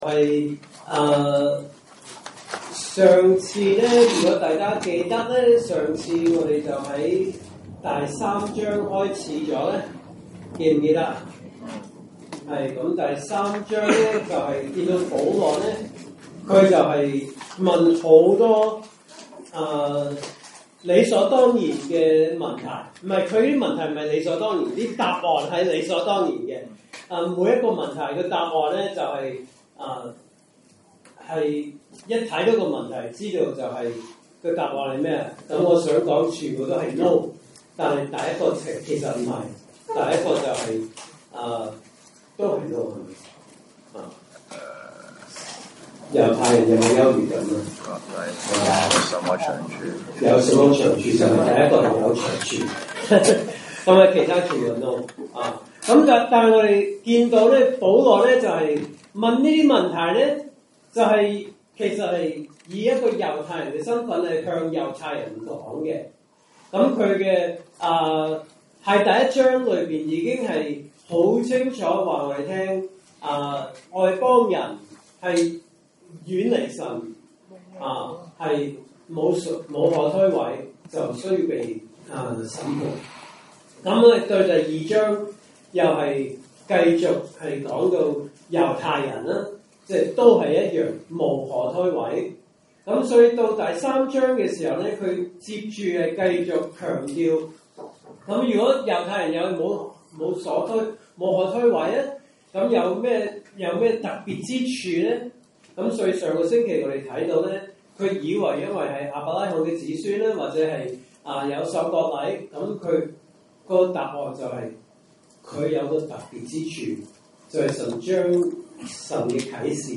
證道信息: “羅馬書 3:9-18”